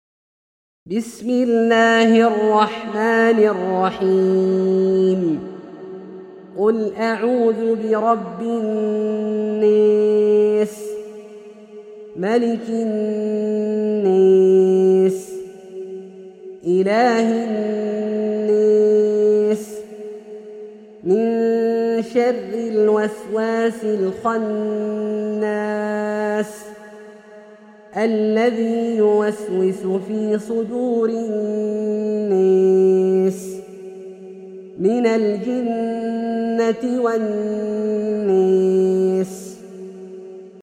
سورة الناس - برواية الدوري عن أبي عمرو البصري > مصحف برواية الدوري عن أبي عمرو البصري > المصحف - تلاوات عبدالله الجهني